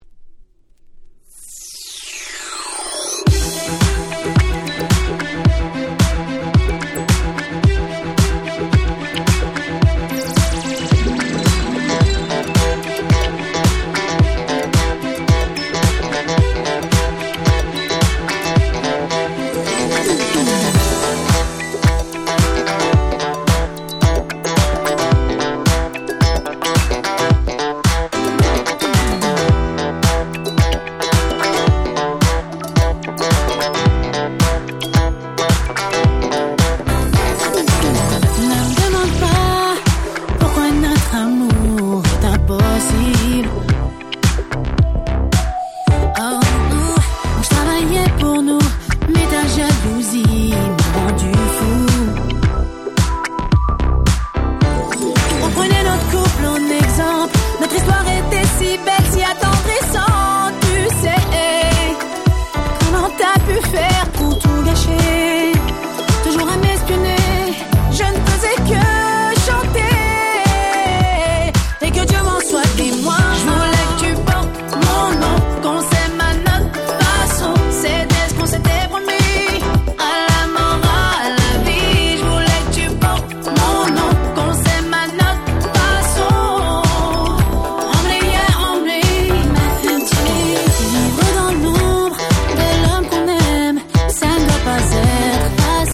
05' Nice French R&B !!
キャッチー系 ユーロ